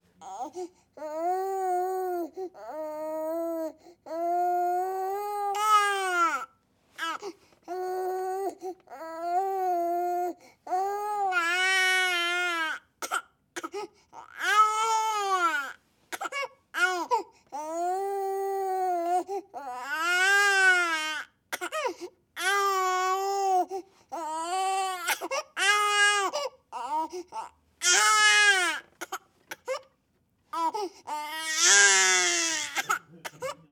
Baby_Crying